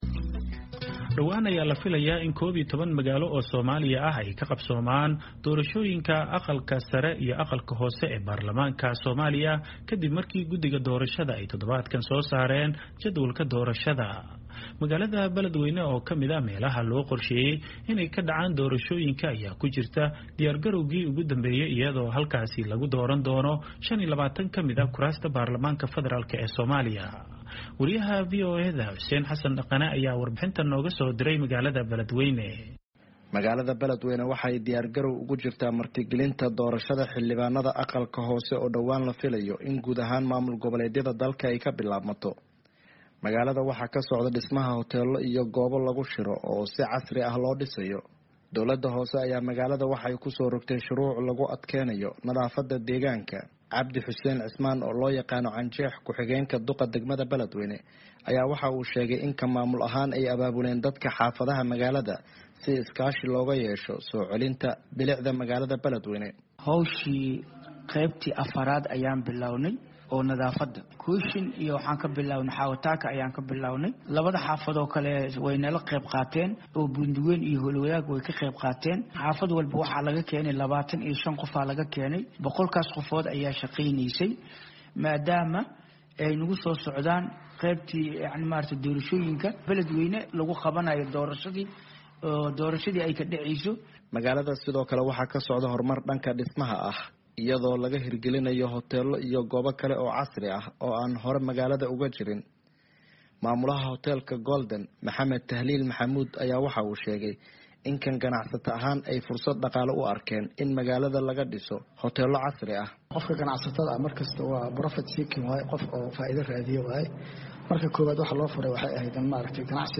BELEDWEYNE —